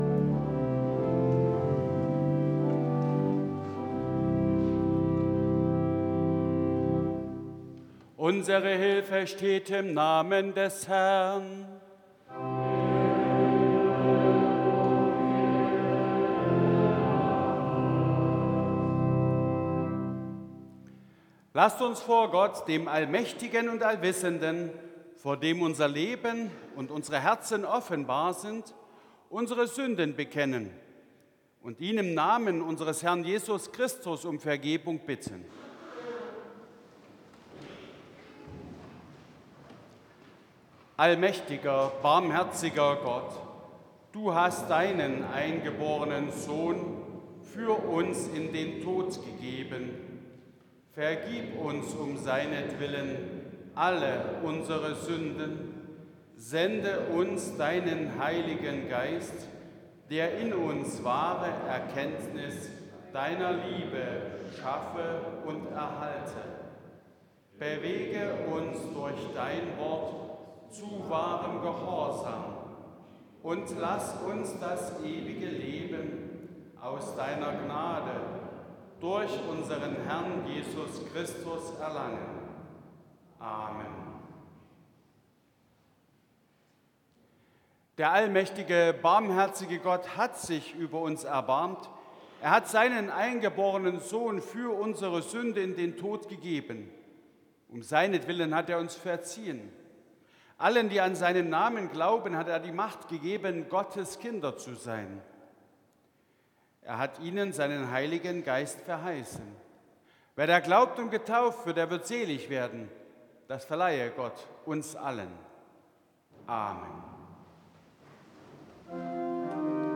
3. Eingangsliturgie Evangelisch-Lutherische St. Johannesgemeinde Zwickau-Planitz
Audiomitschnitt unseres Gottesdienstes vom Letzten Sonntag nach Epipanias 2026.